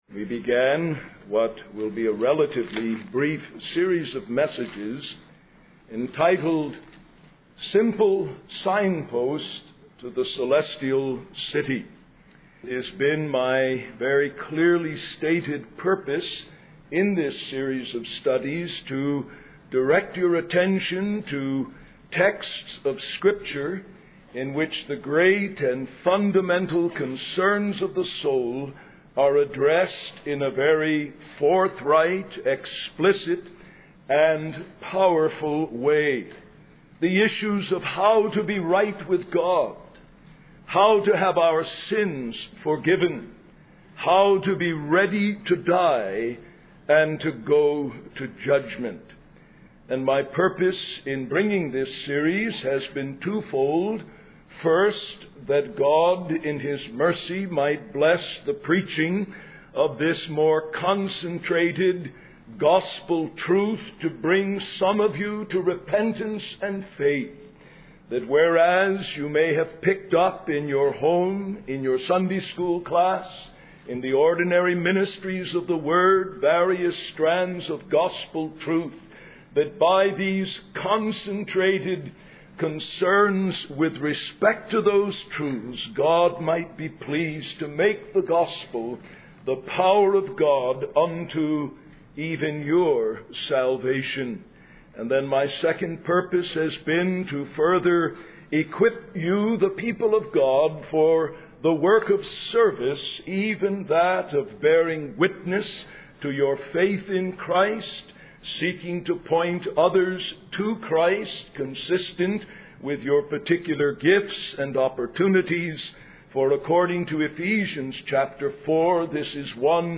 In this sermon, the preacher emphasizes the importance of recognizing the impending day of wrath.